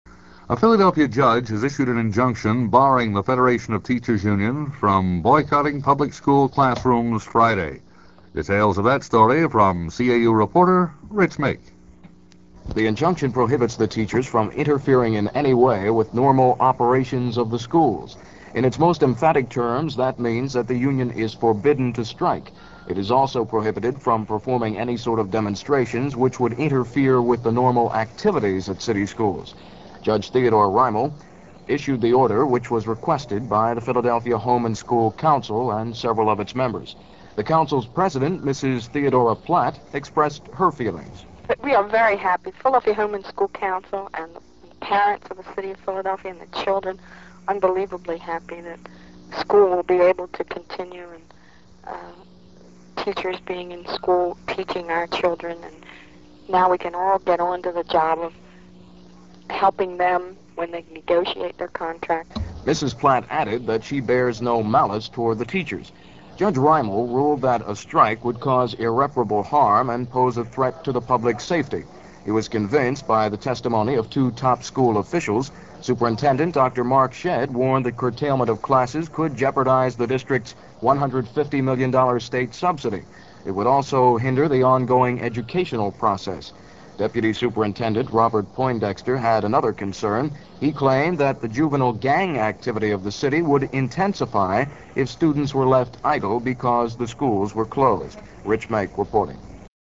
The following clips are from an October 15, 1970 aircheck of WCAU-AM's Morning NewsBeat program, which was all news from 6 to 9 a.m.